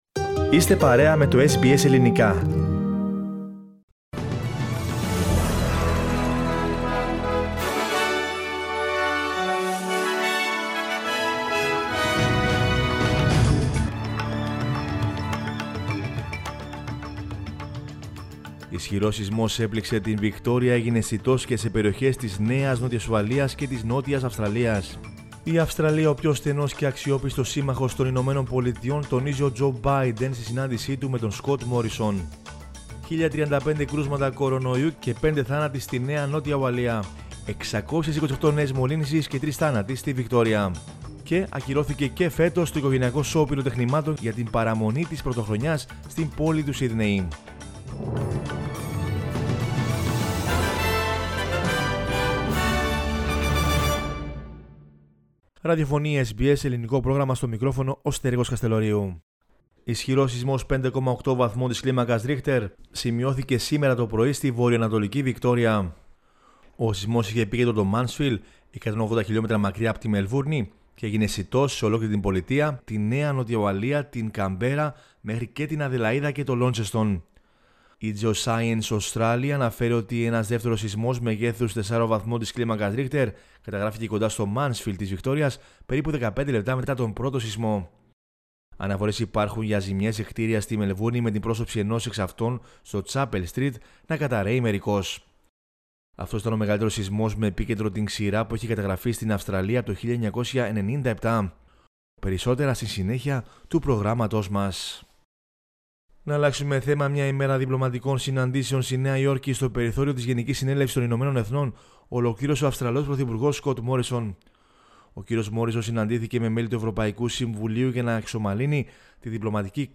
News in Greek from Australia, Greece, Cyprus and the world is the news bulletin of Wednesday 22 September 2021.